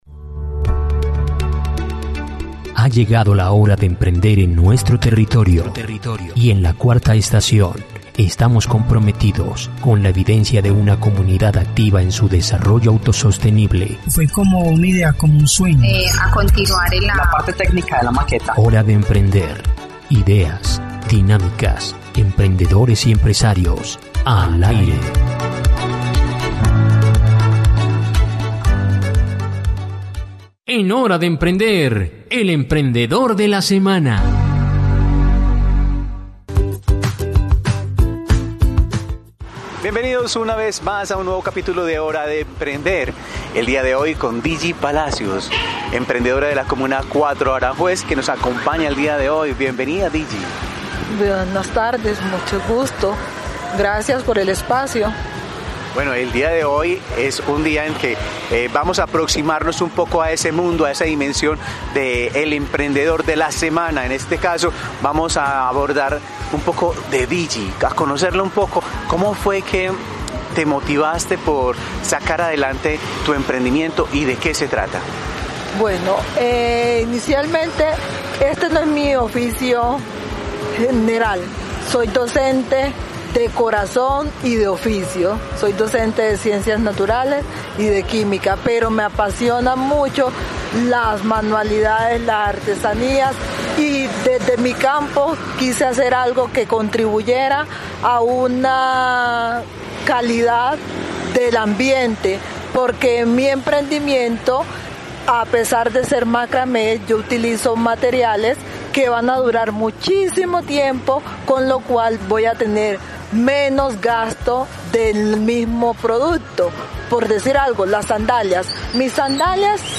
En esta conversación, nos cuenta cómo ha logrado integrar su labor docente con su compromiso con la sostenibilidad ambiental.